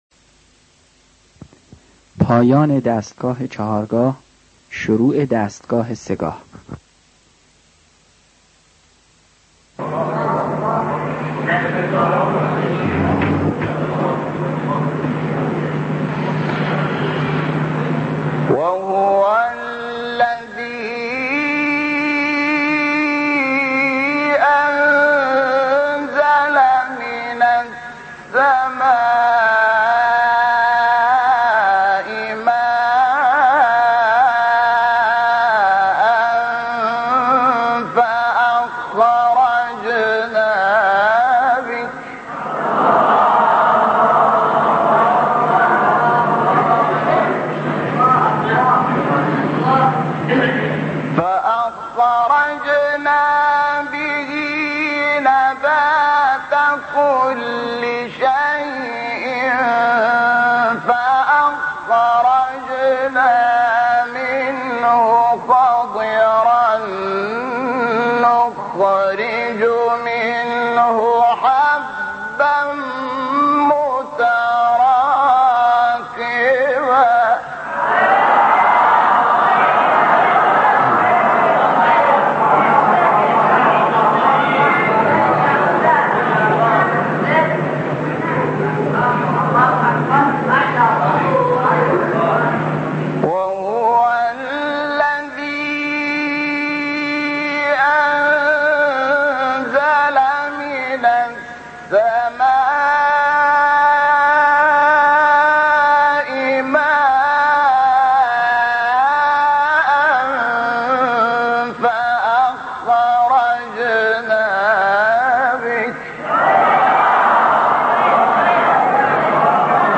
سایت-قرآن-کلام-نورانی-شحات-سه-گاه.mp3